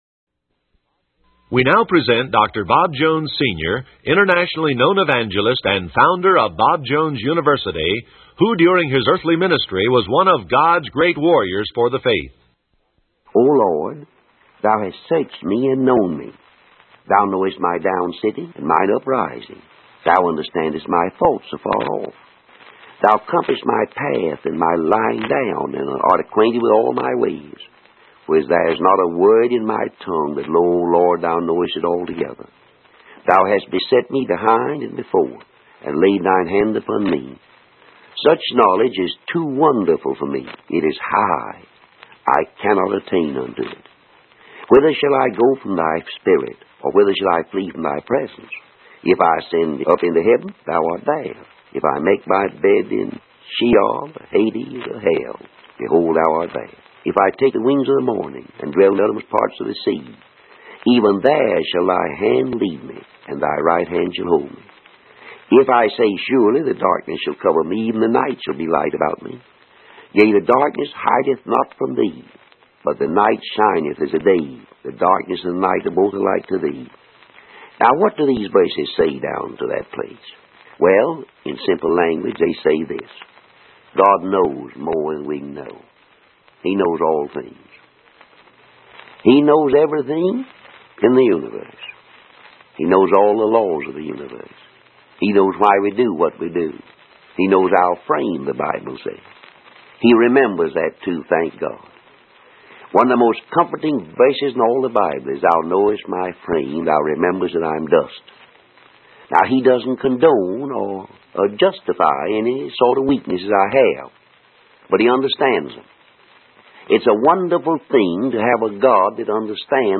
In this sermon, Dr. Bob Jones Sr. emphasizes the limited understanding of human beings compared to God's infinite knowledge.